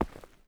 Terrarum/assets/mods/basegame/audio/effects/mining/ROCK.9.wav at 23cae8ed739e09439f8d4ee98637c720b466752d
ROCK.9.wav